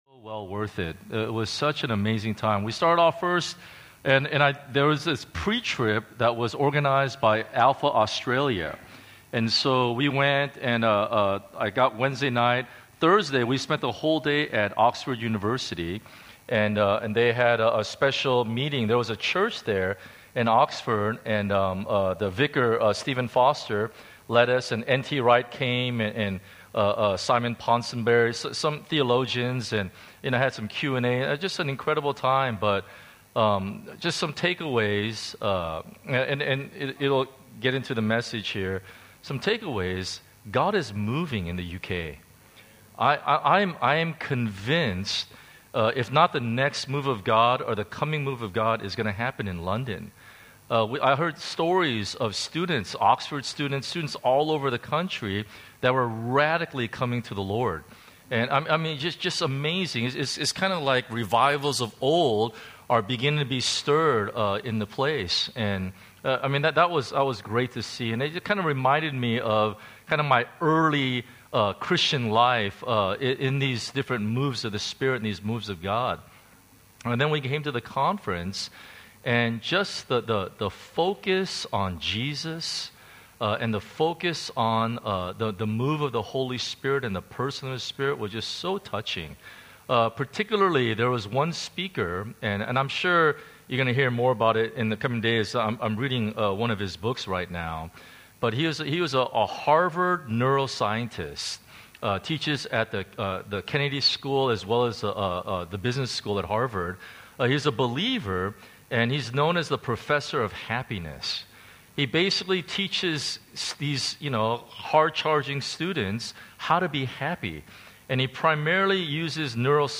Sermons from Solomon's Porch Hong Kong.